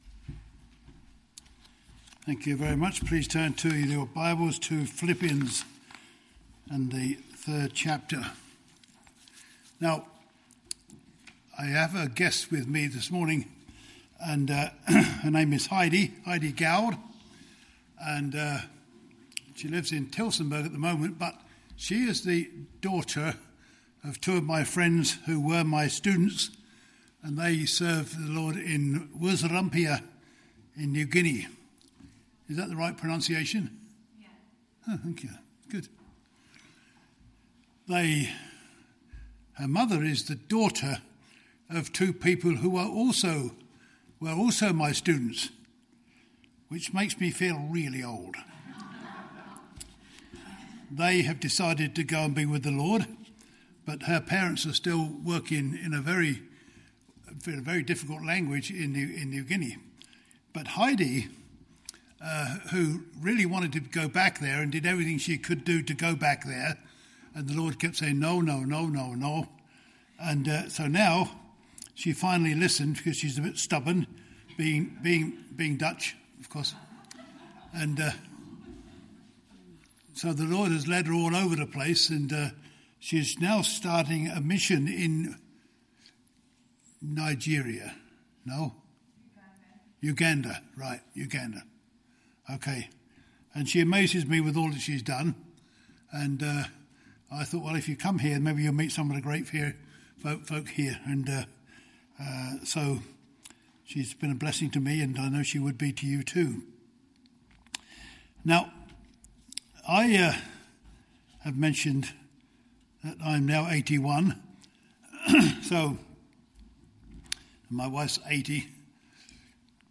Passage: Phil 3:17 - 4:1 Service Type: Family Bible Hour « What Is Man